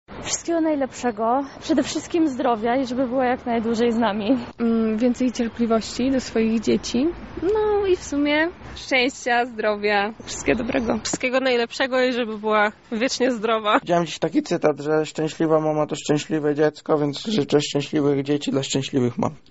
Czego życzymy naszym mamom? – na to pytanie również odpowiadają lublinianinie:
sonda-2.mp3